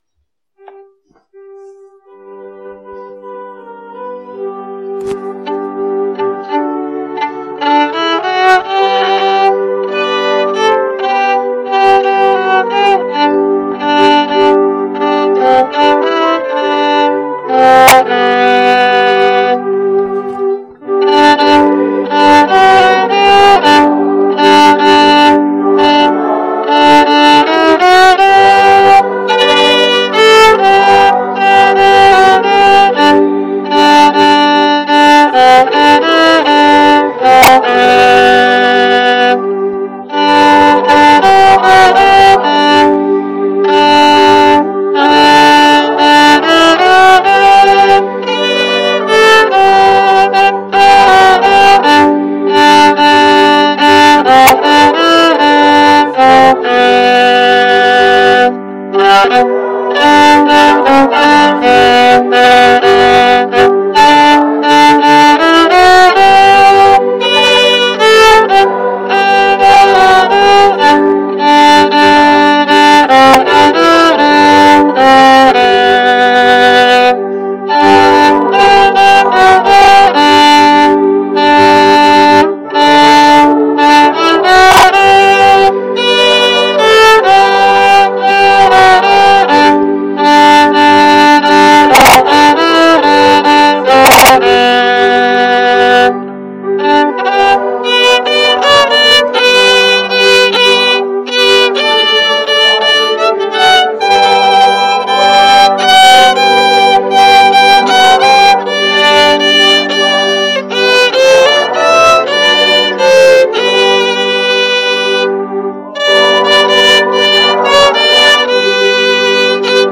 Special performances
Hymn 248